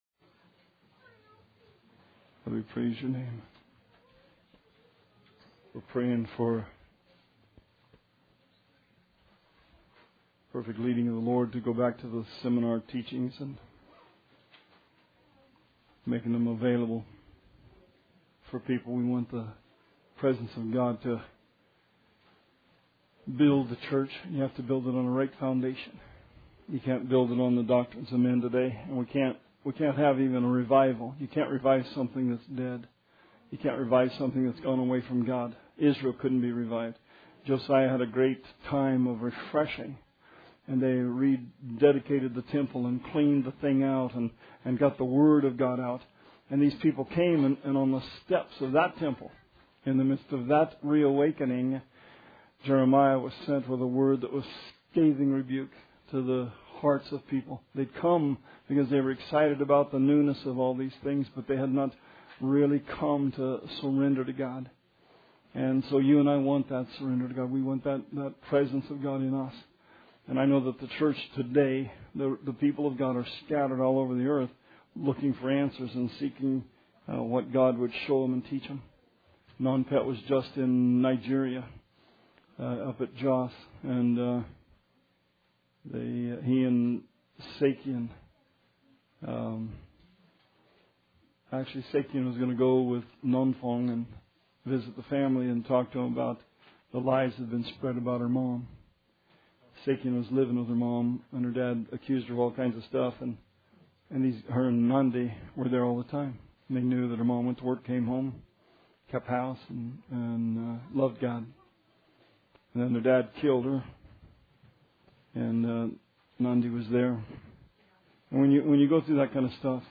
Bible Study 1/20/16